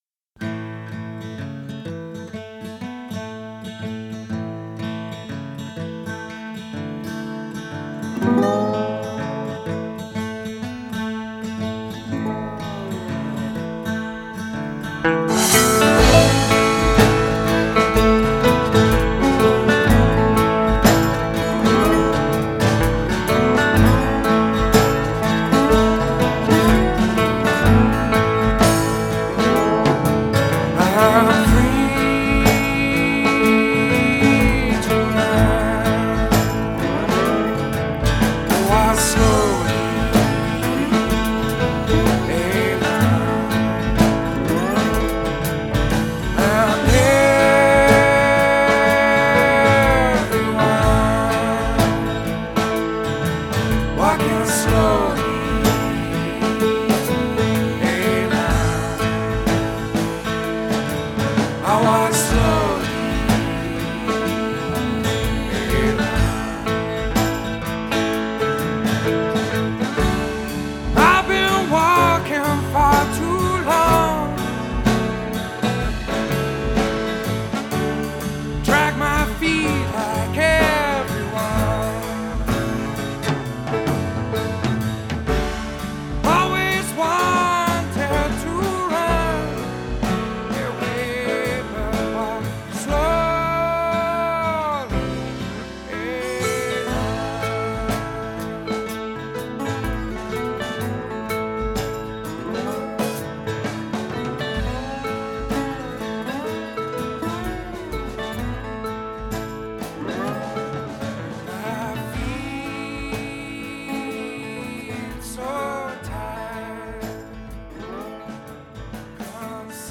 brought a refreshingly bluesy tinge to rock